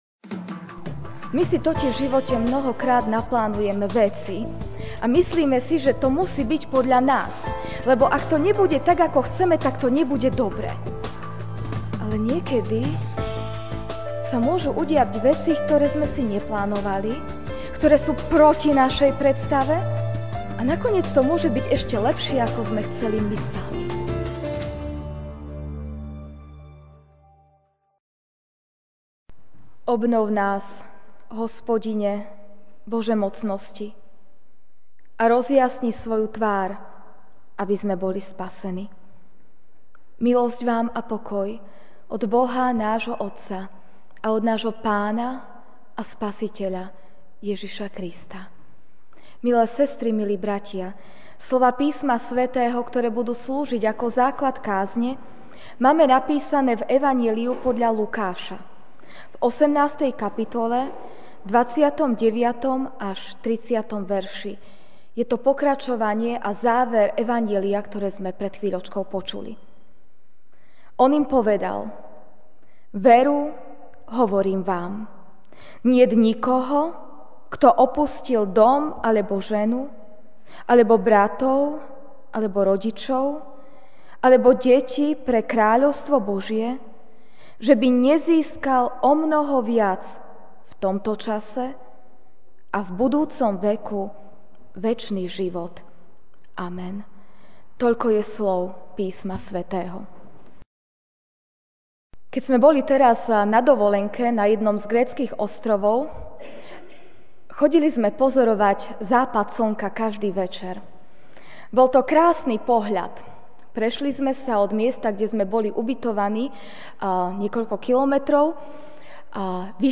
Večerná kázeň: Nasledovanie & učeníctvo (Lk. 18, 29-30)